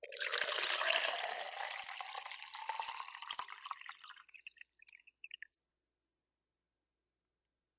液体的声音 " 浇水 4
描述：从一个杯子到一个空杯子以适中的速度倒水。 使用Sony IC录音机录制，使用Fl Studio中的Edison进行清理。
Tag: 浇注 液体 倾倒 索尼-IC - 录音机